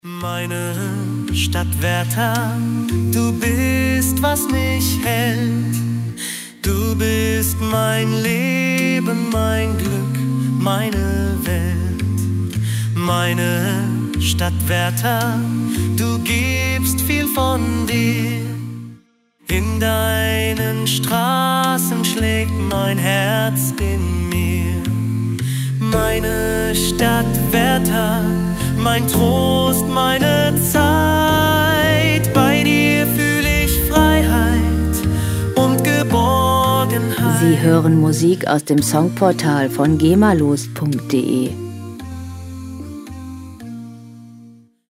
gema-freie Songs aus der Rubrik "Volkslieder"
Musikstil: a cappella
Tempo: 112 bpm
Tonart: B-Dur
Charakter: gefällig, sonor
Instrumentierung: Männergesang, E-Piano, Fingerschnipsen